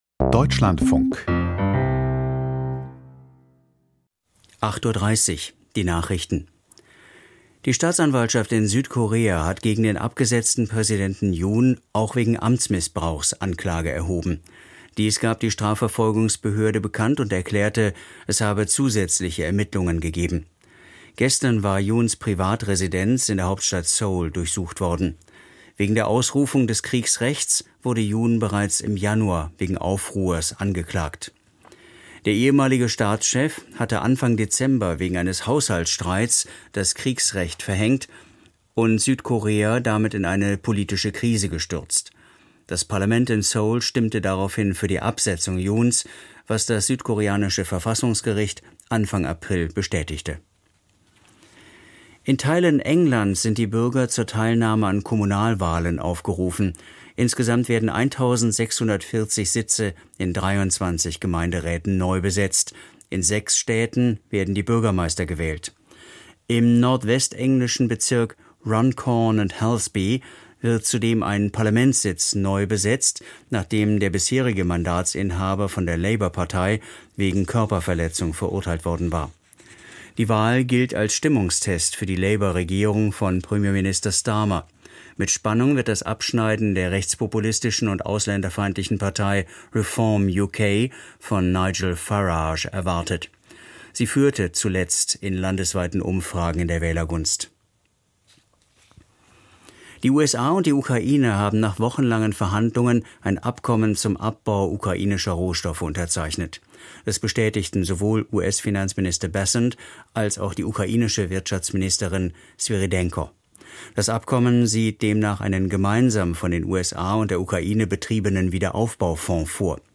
Die wichtigsten Nachrichten aus Deutschland und der Welt.